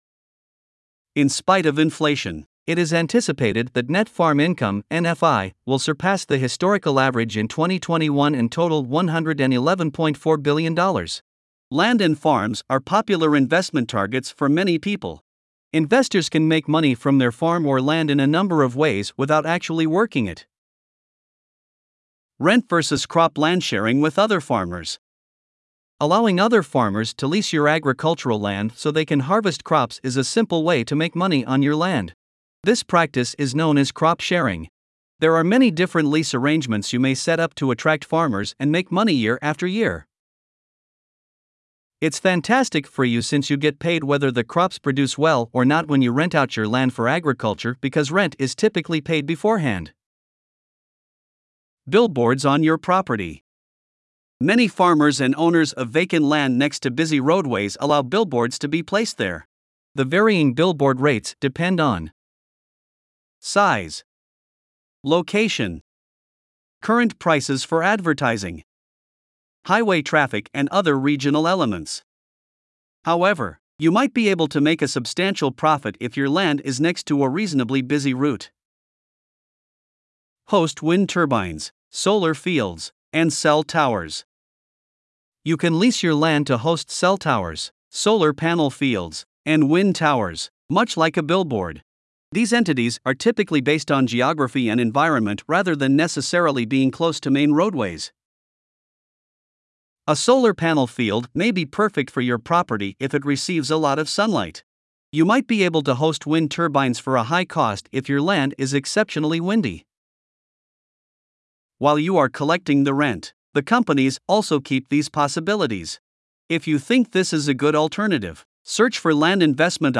Voiceovers-Voices-by-Listnr_12.mp3